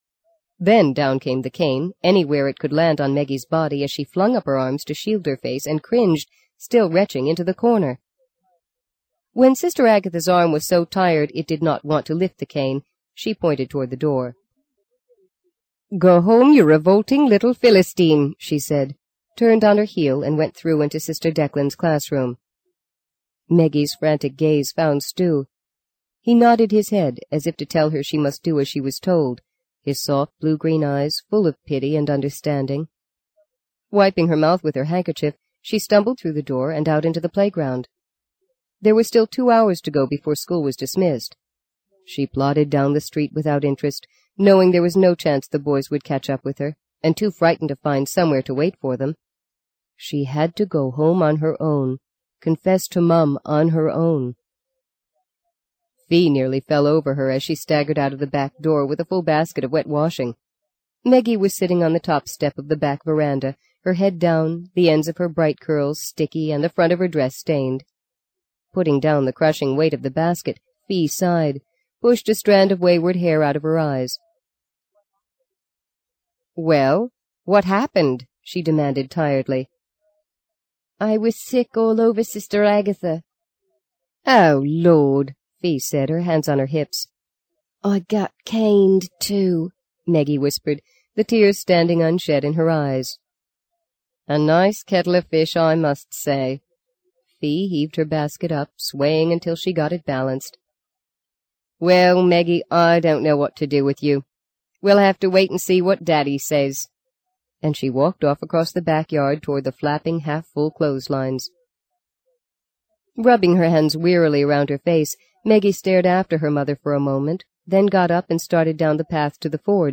在线英语听力室【荆棘鸟】第二章 10的听力文件下载,荆棘鸟—双语有声读物—听力教程—英语听力—在线英语听力室